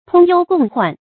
通忧共患 tōng yōu gòng huàn
通忧共患发音